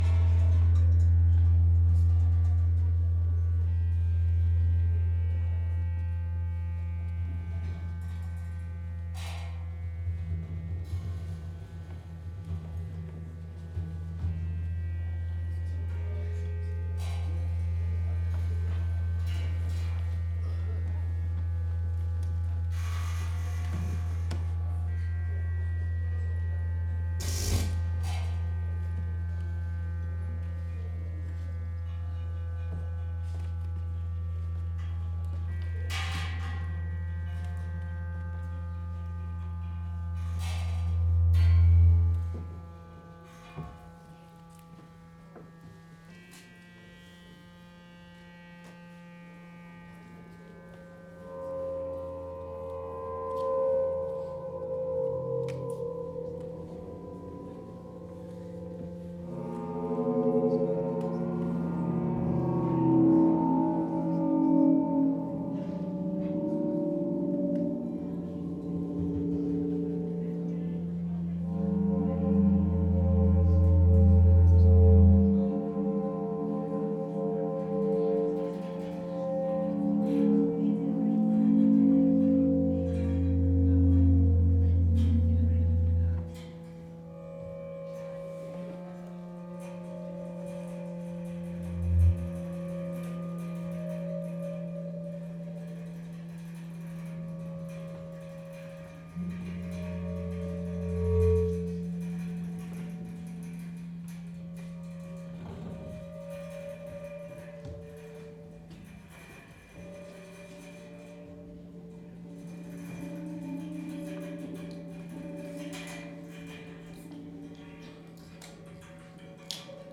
Impro Libre y Noise
Lengua del juglar, Ciudad de Rosario